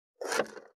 476,切る,包丁,厨房,台所,野菜切る,咀嚼音,ナイフ,調理音,まな板の上,料理,